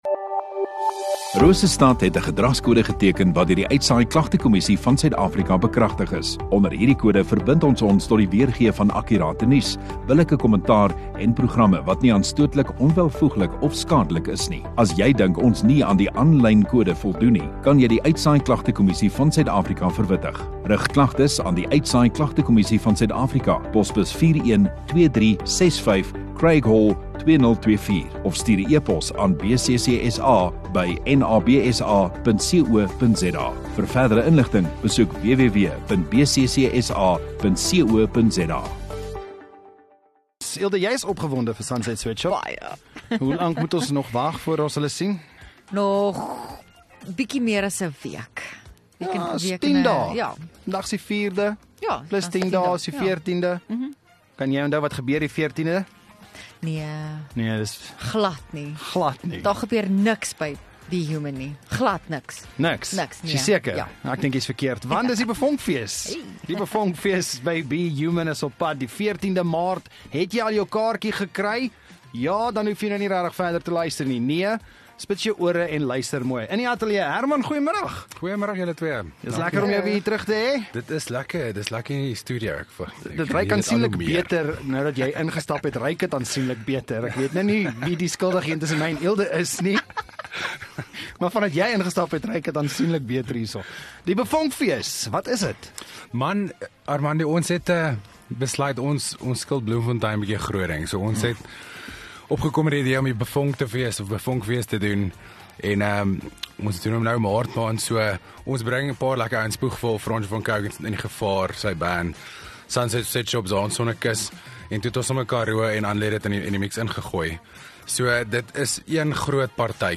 View Promo Continue Radio Rosestad Installeer Vermaak en Kunstenaars Onderhoude 4 Mar BeVonk musiekfees